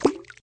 splash_2.ogg